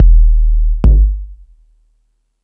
Lotsa Kicks(56).wav